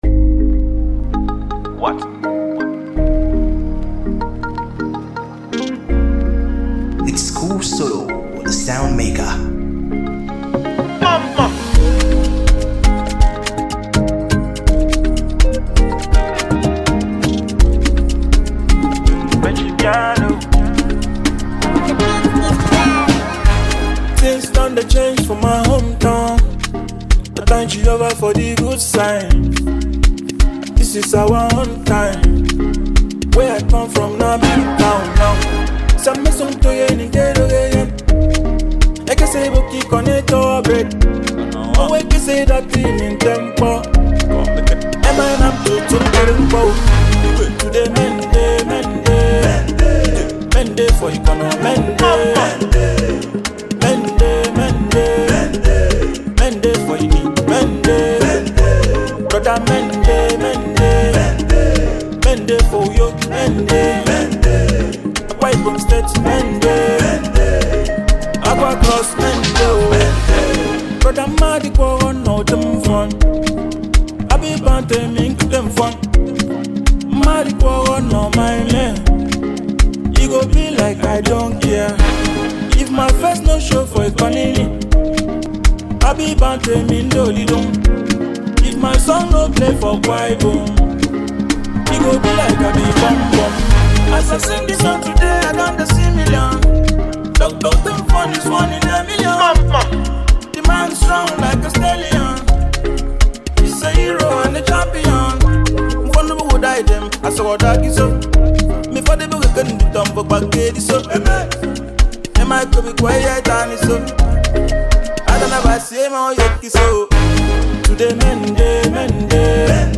a vibrant Afrobeat anthem
an ideal Afrobeats sound